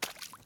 tbd-station-14/Resources/Audio/Effects/Footsteps/puddle4.ogg at 0bbe335a3aec216e55e901b9d043de8b0d0c4db1
puddle4.ogg